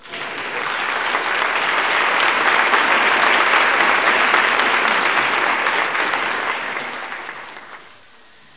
APPLAUS1.mp3